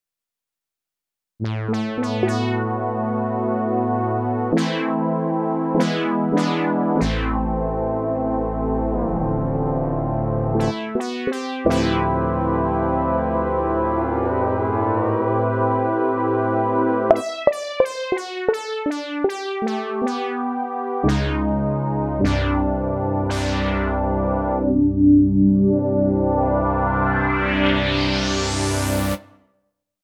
Within a week I have made these sounds, and recordings of them, using 24 bit 44.1kHz TOS link:
synthsupermoogtvpad.mp3